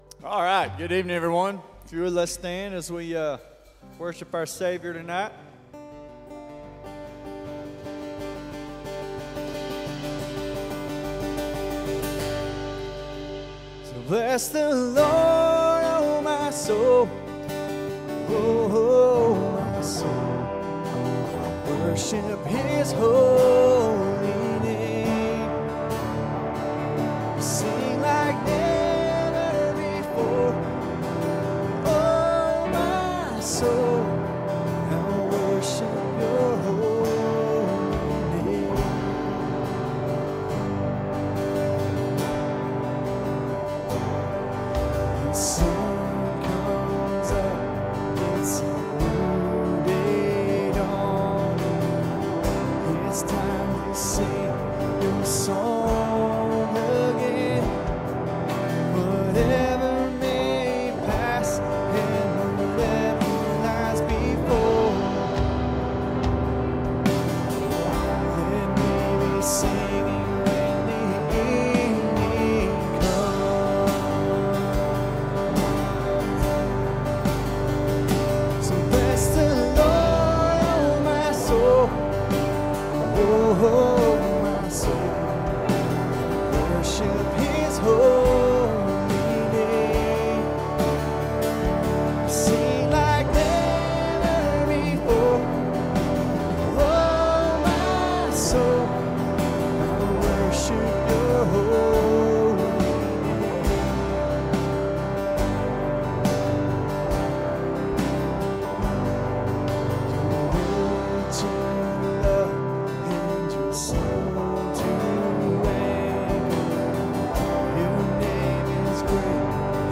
Wednesday evening sermon on a key lesson found in Jonah 1:4-10 - You can run, but you can't hide.